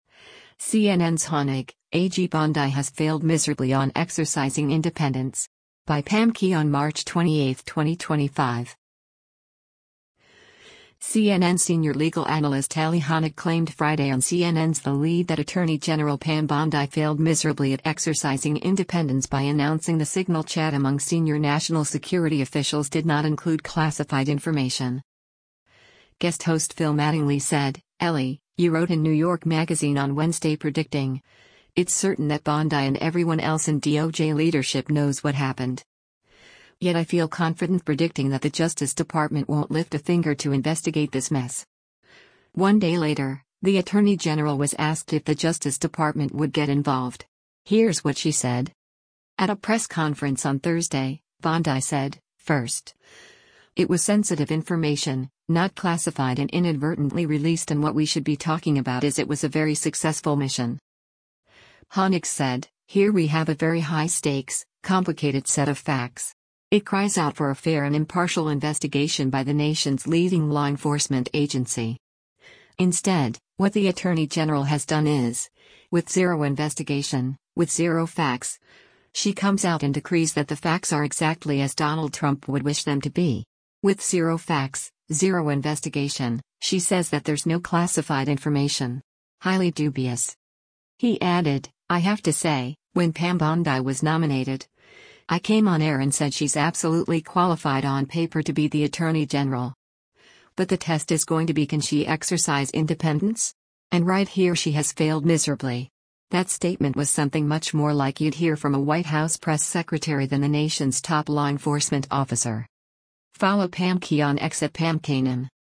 At a press conference on Thursday, Bondi said, “First, it was sensitive information, not classified and inadvertently released and what we should be talking about is it was a very successful mission.”